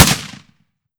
7Mag Bolt Action Rifle - Gunshot B 001.wav